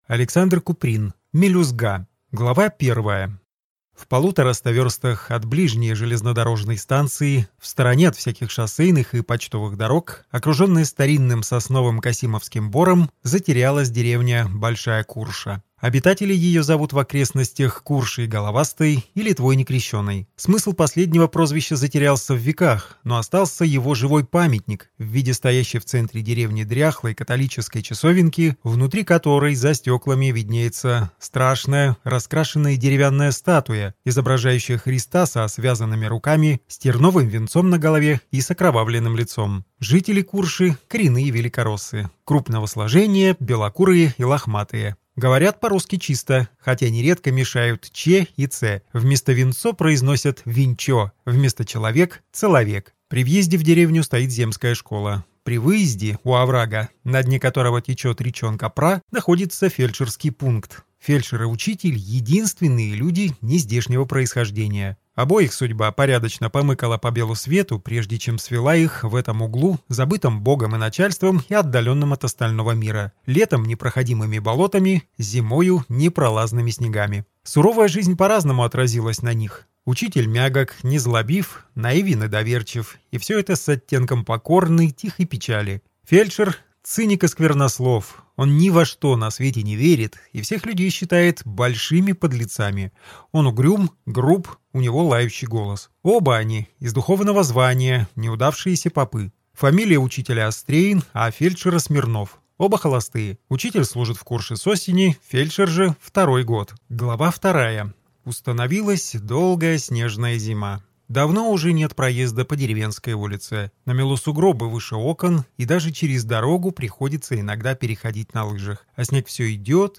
Aудиокнига Мелюзга